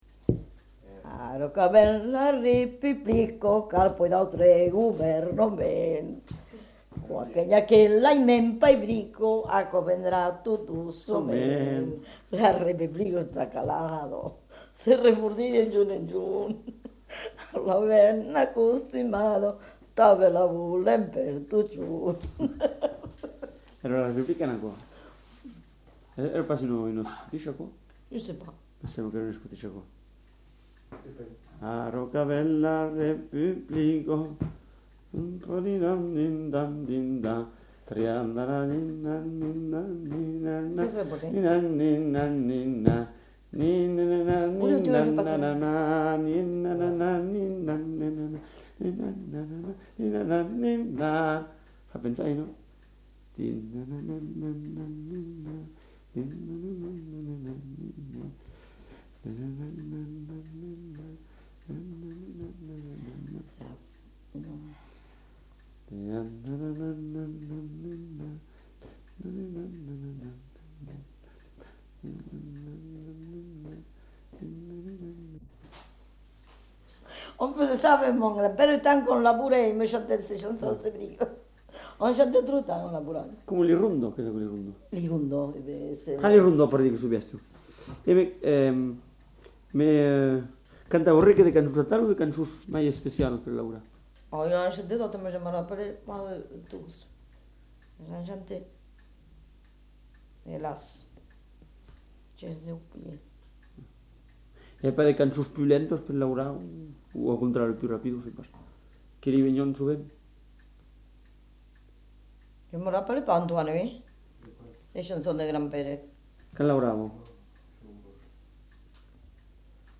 Lieu : Lanta
Genre : chant
Effectif : 1
Type de voix : voix de femme
Production du son : chanté
Danse : scottish